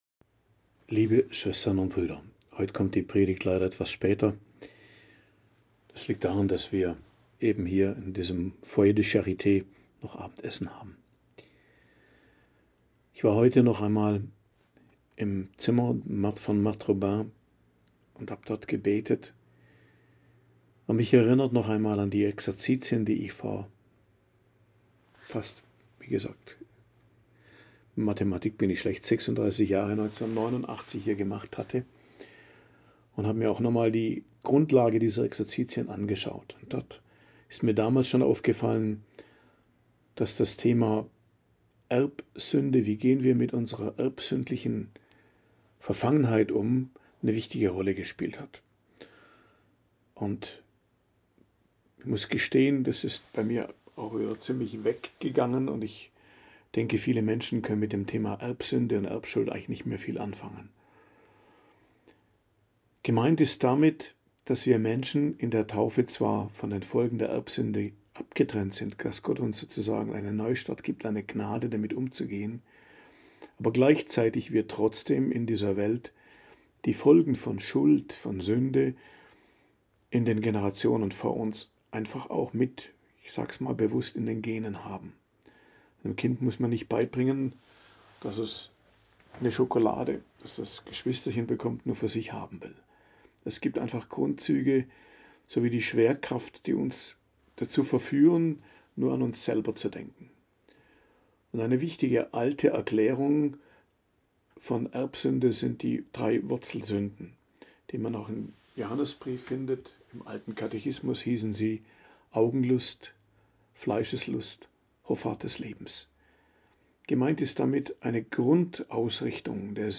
Predigt am Donnerstag der 19. Woche i.J., 14.08.2025